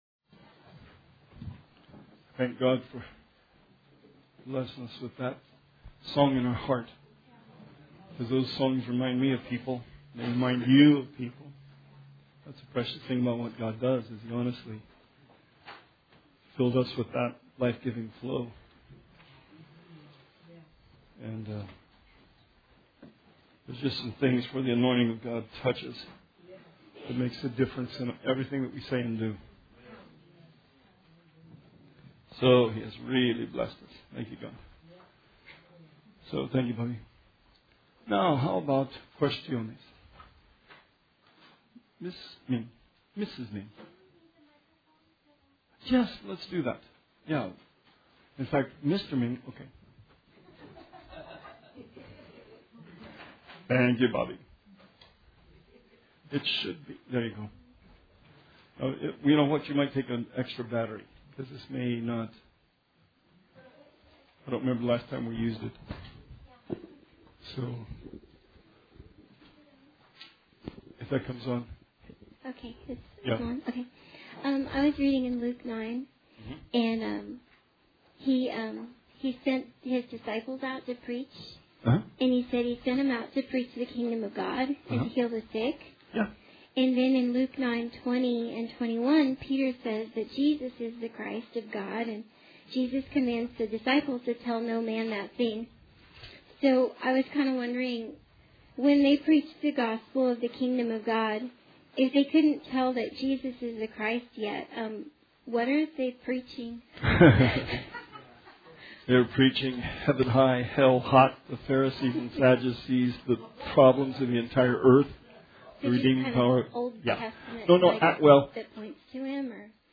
Bible Study 1/9/19